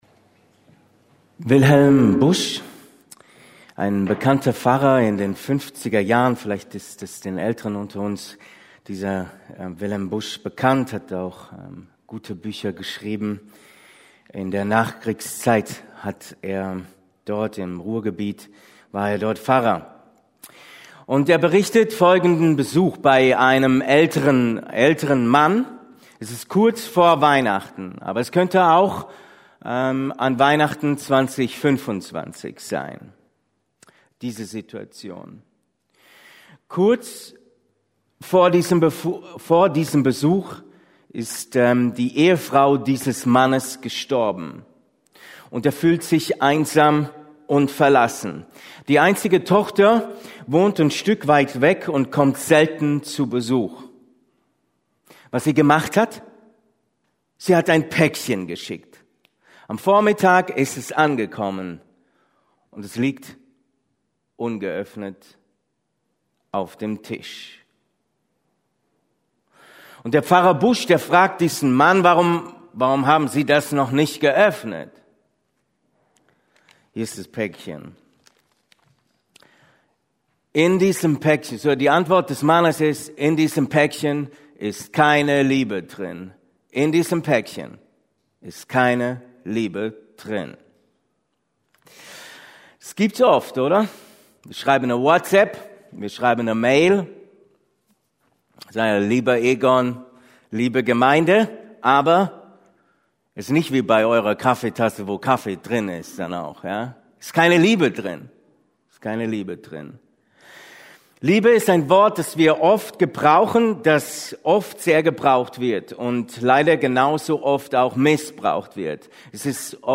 Gott ist Liebe – Predigten: Gemeinschaftsgemeinde Untermünkheim